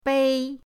bei1.mp3